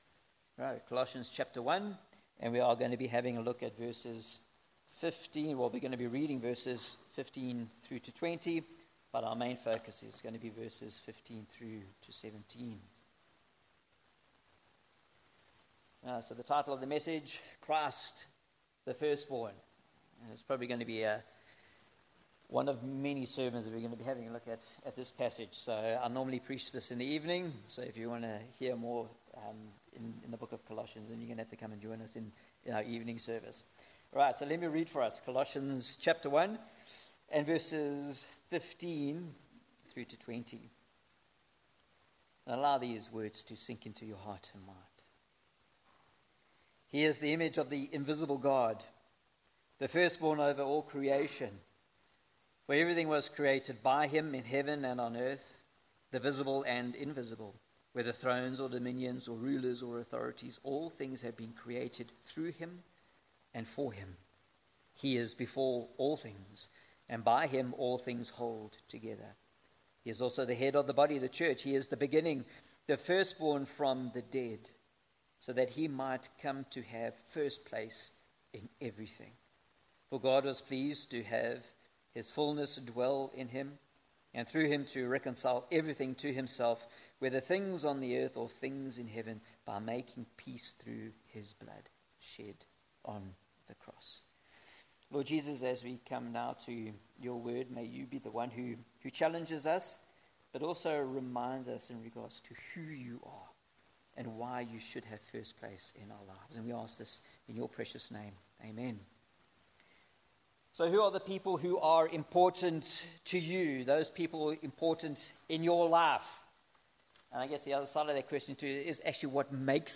Passage: Colossians 1:15-20, Psalm 89 Service Type: Sunday Morning